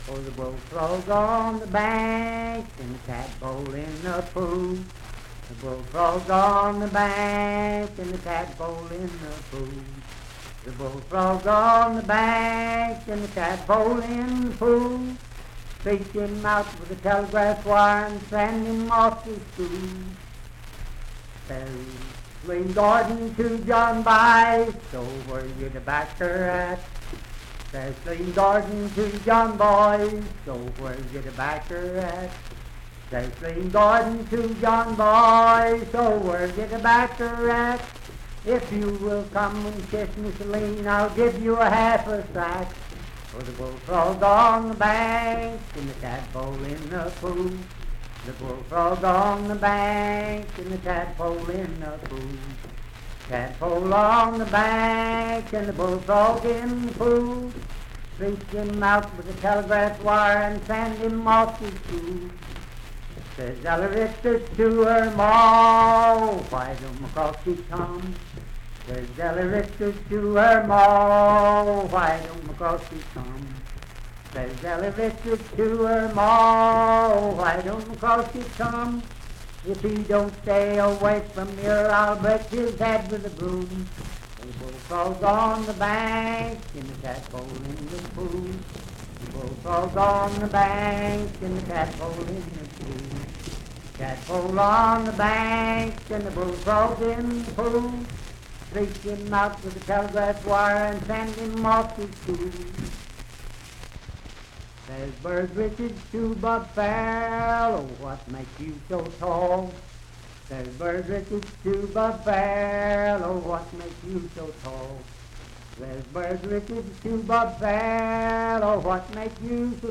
Unaccompanied vocal music
Dance, Game, and Party Songs, Humor and Nonsense
Voice (sung)